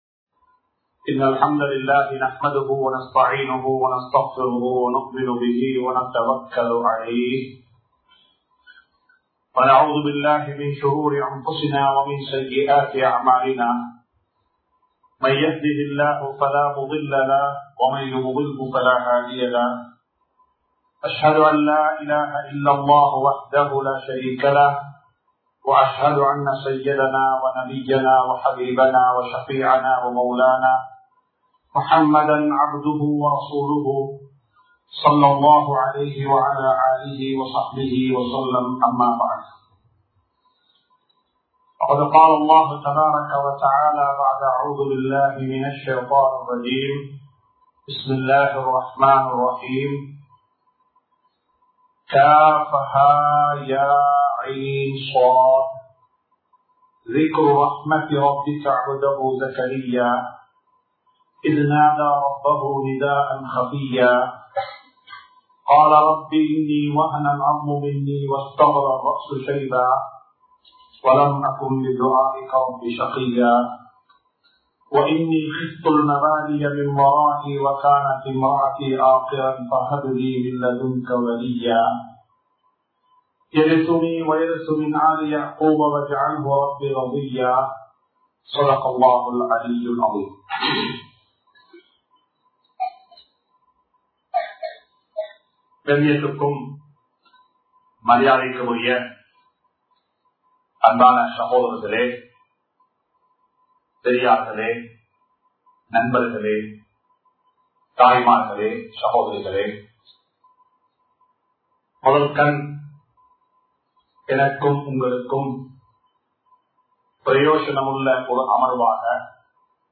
Munmathiriyana Petrorkal-Day01(முன்மாதிரியான பெற்றோர்கள்-Day01) | Audio Bayans | All Ceylon Muslim Youth Community | Addalaichenai
MICH Hall Lily Avenue